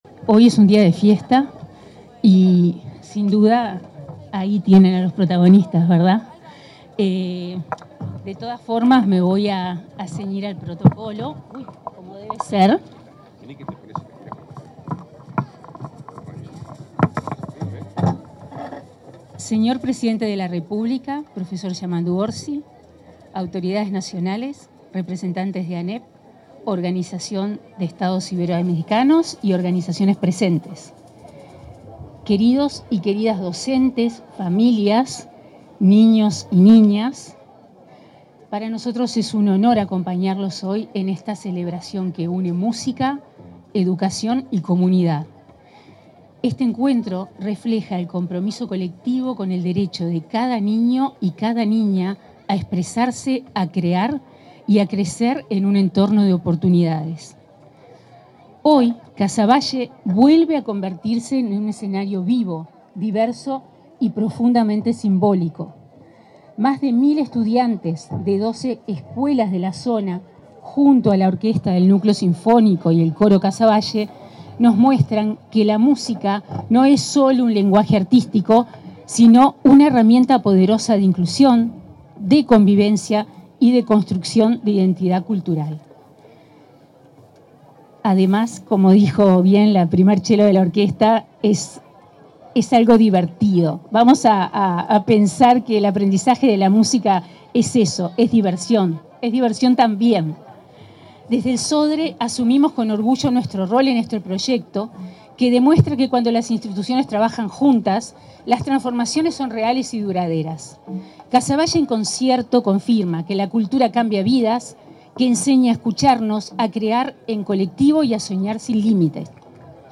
La vicepresidenta del Sodre, Alejandra Moreira, hizo uso de la palabra en la actividad denominada “Casavalle en concierto”, de la que participaron más